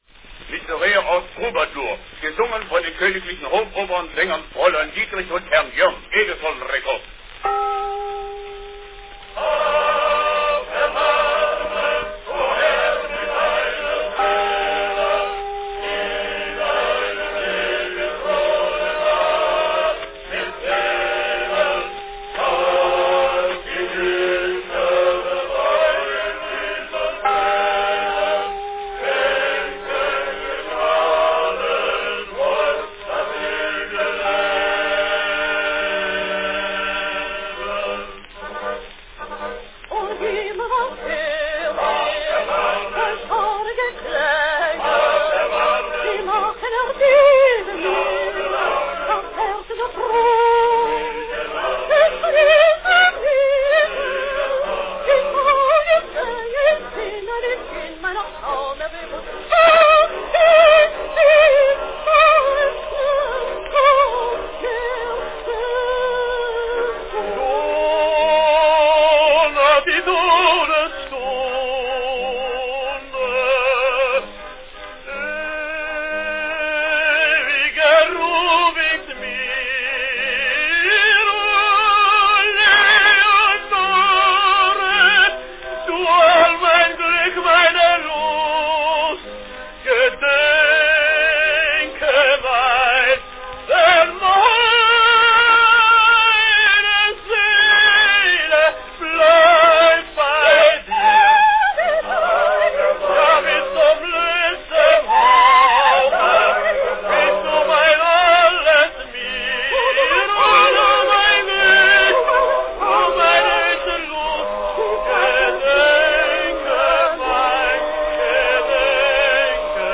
Cylinder of the Month
Category Duet
Enjoy this well-recorded operatic selection
soprano
tenor
This cylinder, recorded in Berlin in 1906, is notable also for the clarity of the chorus, which is a bit unusual for a wax cylinder of this period.